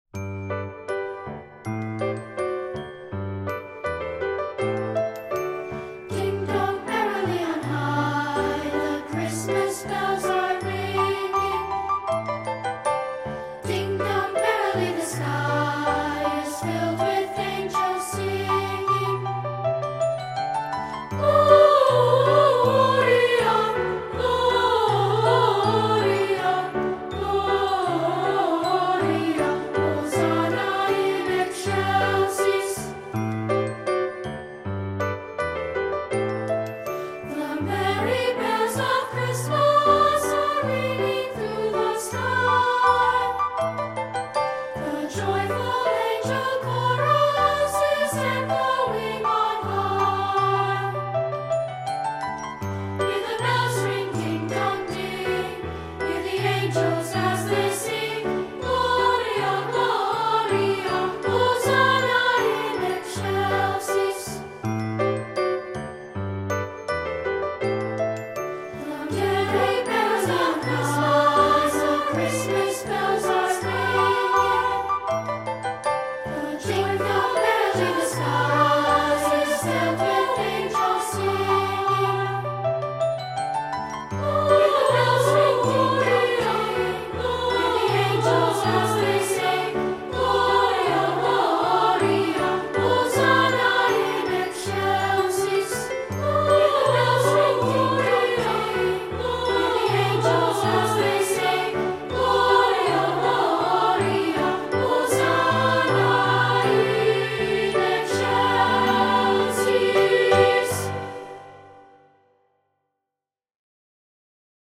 Voicing: Two-part